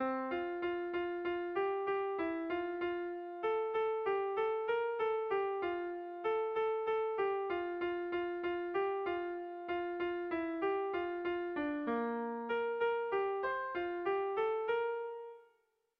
Erlijiozkoa
Lauko handia (hg) / Bi puntuko handia (ip)
AB